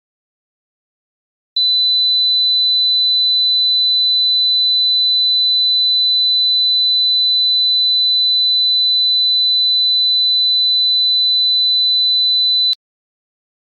60歳以上も聞こえる音。蝉の鳴き声ほどの周波数と言われています。